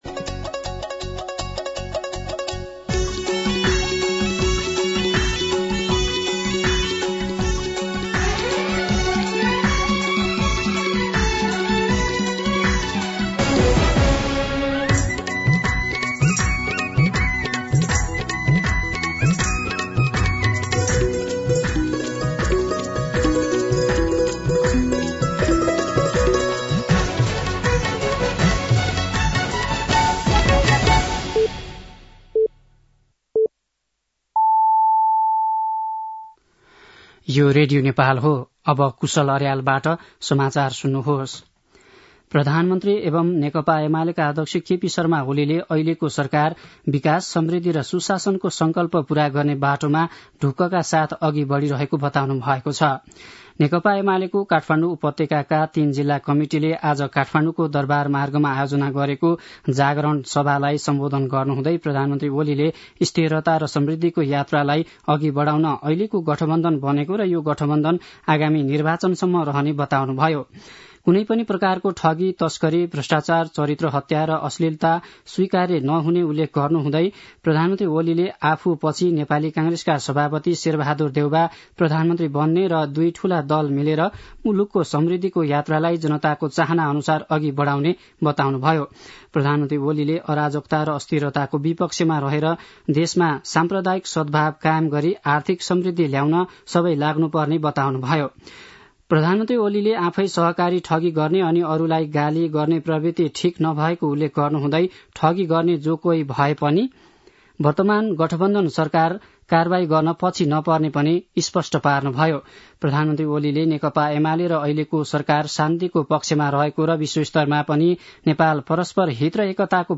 दिउँसो ४ बजेको नेपाली समाचार : ८ मंसिर , २०८१
4-pm-nepali-news-1-4.mp3